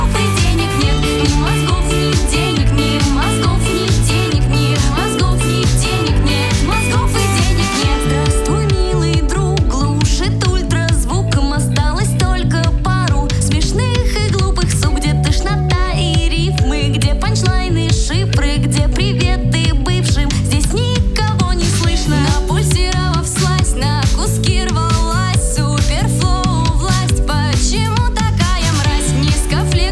Жанр: Поп / Инди / Русские